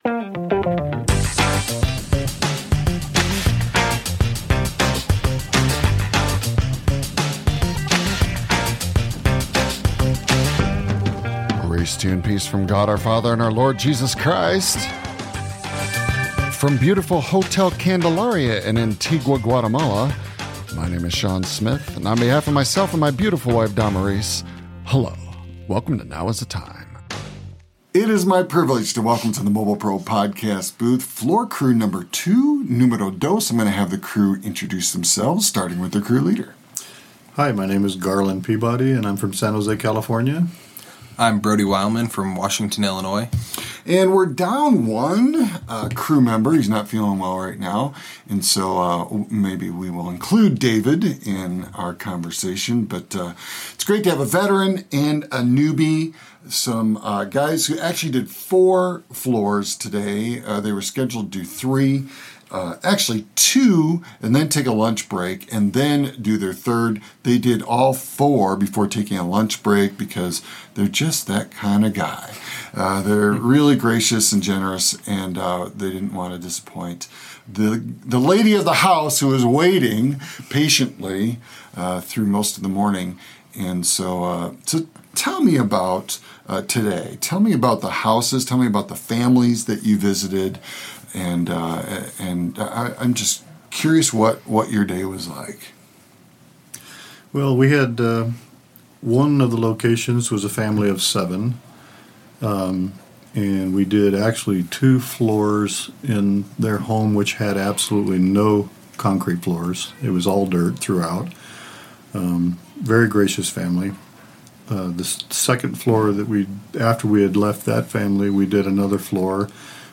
This podcast was created and published completely within an iOS device (iPhone, iPad, or iPod Touch) - no computer, no external batteries, or external power.